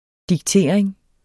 diktering substantiv, fælleskøn Bøjning -en, -er, -erne Udtale [ digˈteɐ̯ˀeŋ ] Betydninger 1. påtvingelse af en bestemt adfærd, udvikling eller tilstand Se også diktat Drop jeres diktering af, hvad jeg er og ikke er.